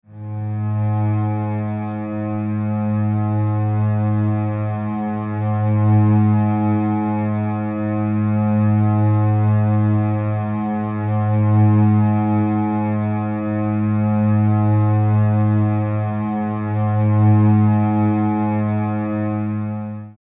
Music tones for String Animation Below
02_Low_Gs.mp3